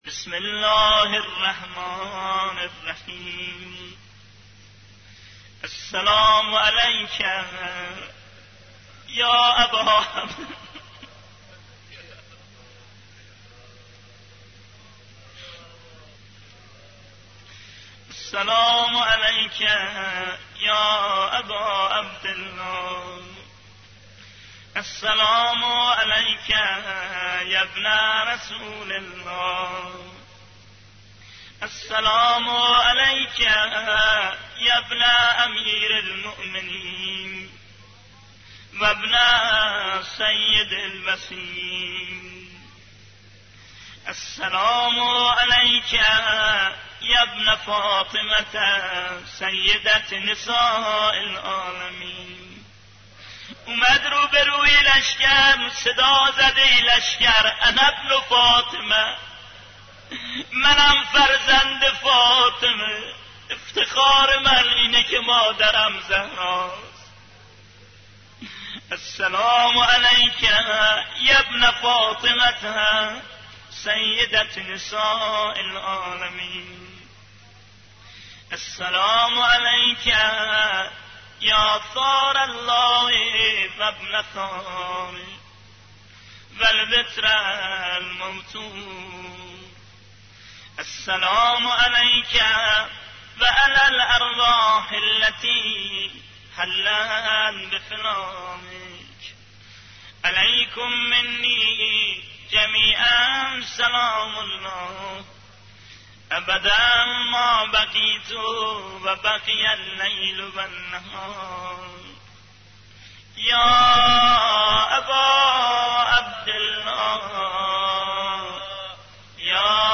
صوت | زیارت عاشورا با نوای «حاج صادق آهنگران»